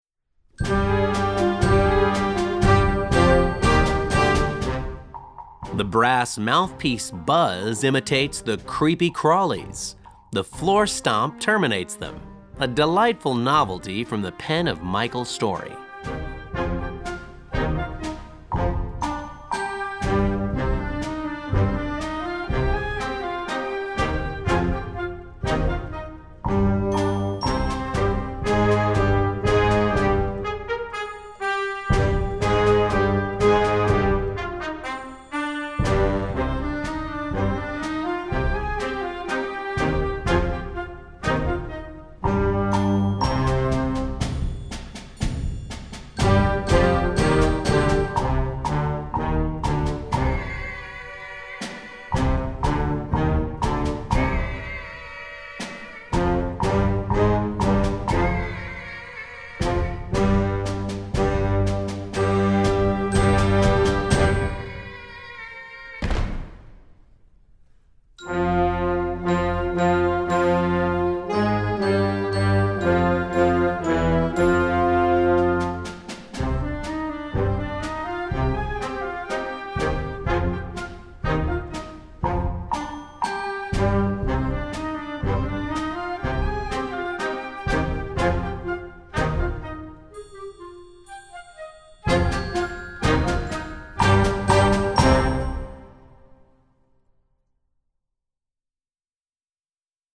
Gattung: Jugendblasorchester
Besetzung: Blasorchester